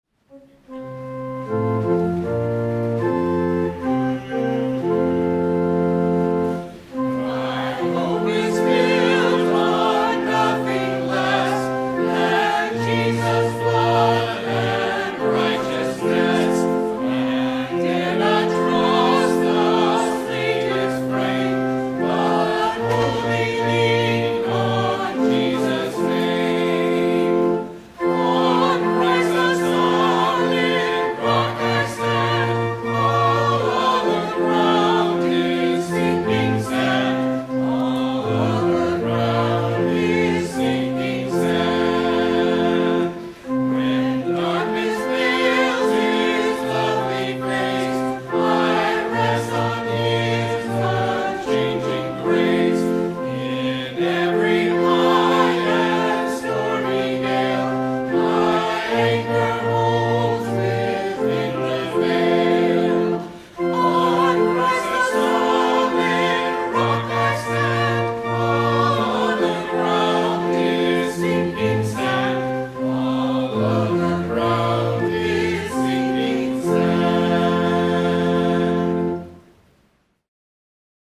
Hymn